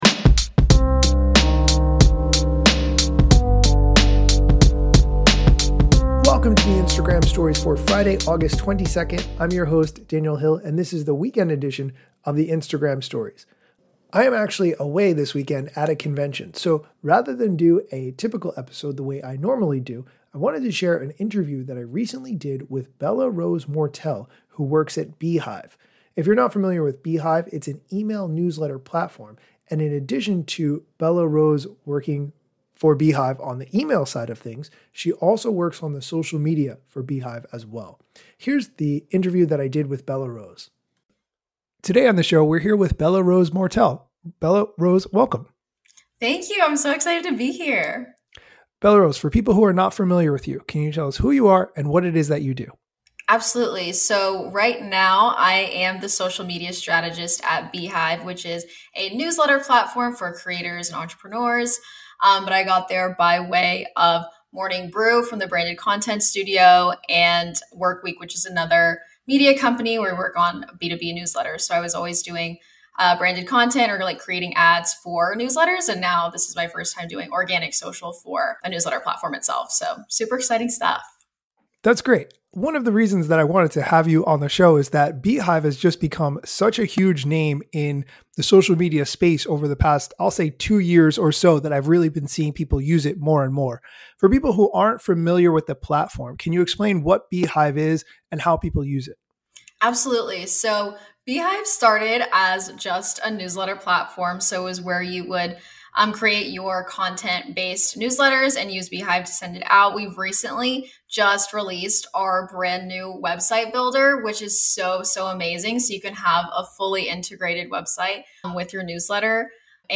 Today I interview